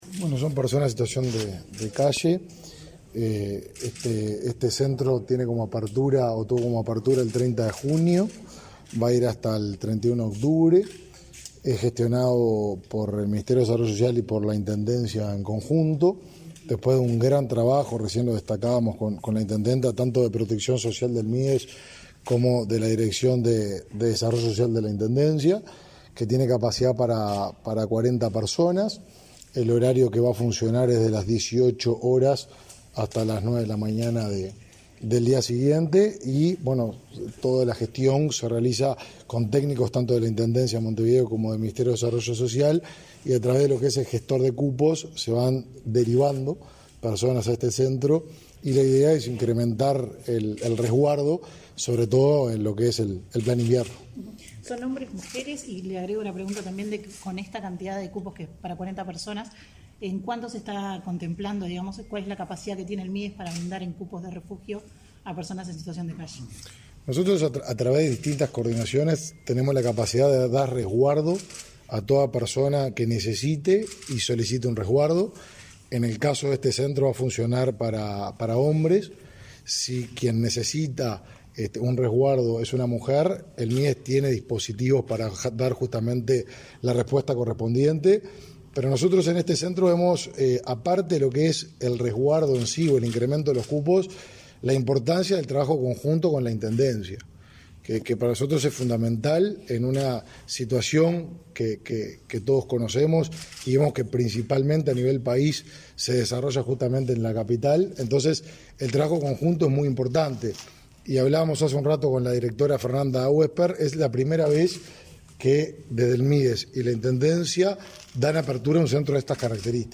Declaraciones a la prensa del ministro de Desarollo Social, Martín Lema
Al respecto, el jerarca efectuó declaraciones a la prensa.